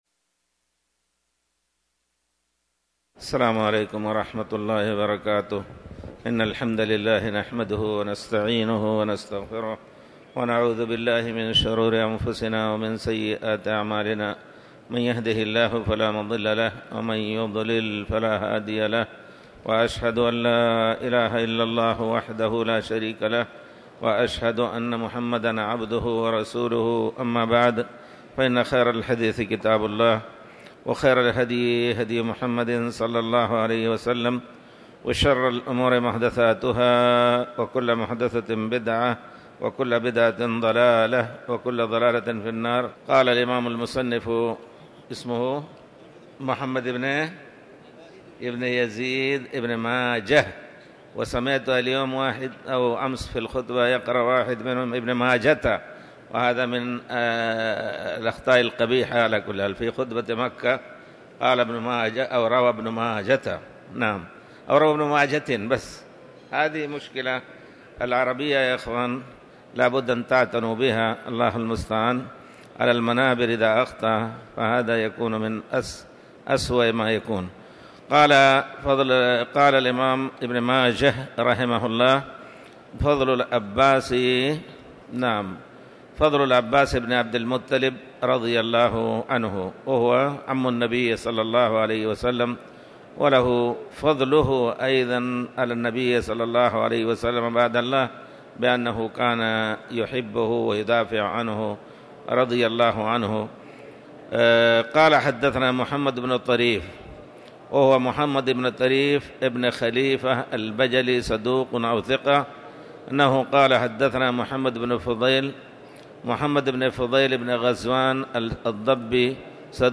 تاريخ النشر ١٠ ذو القعدة ١٤٤٠ هـ المكان: المسجد الحرام الشيخ